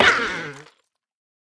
Minecraft Version Minecraft Version 25w18a Latest Release | Latest Snapshot 25w18a / assets / minecraft / sounds / mob / armadillo / death4.ogg Compare With Compare With Latest Release | Latest Snapshot
death4.ogg